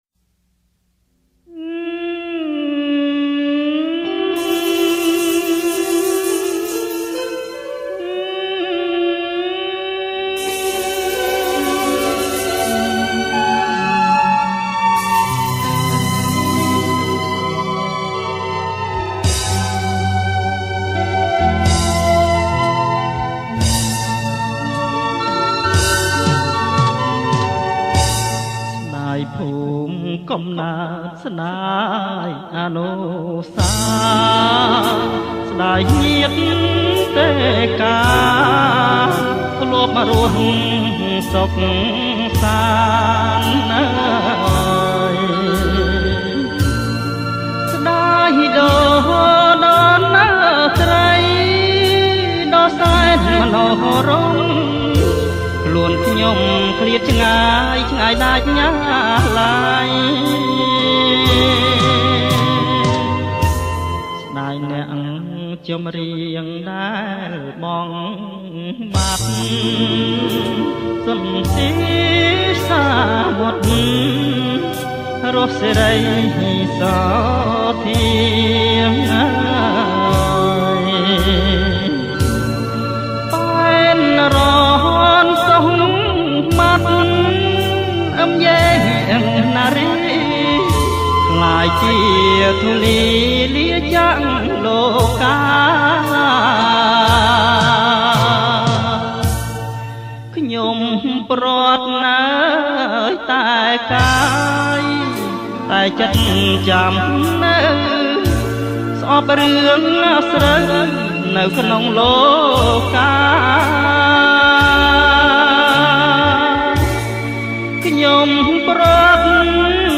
• ប្រគំជាចង្វាក់ Slow
ប្រគំជាចង្វាក់  Slow